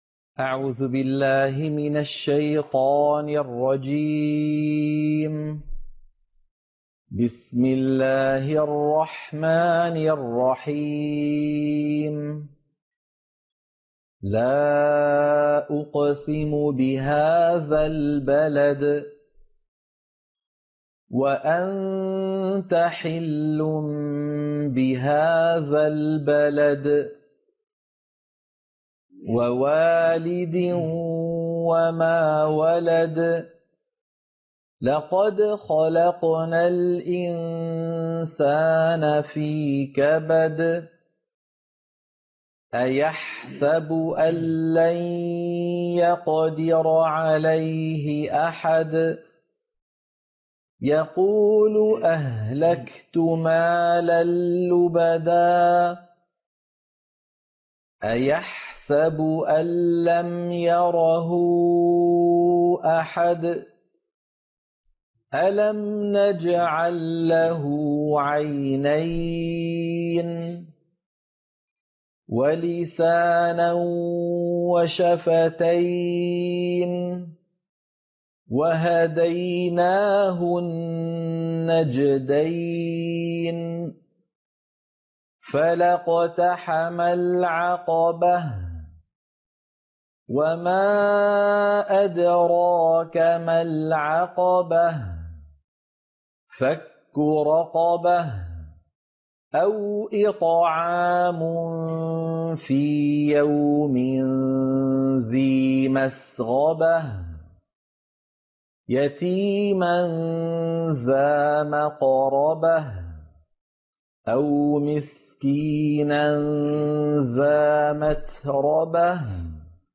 عنوان المادة سورة البلد - القراءة المنهجية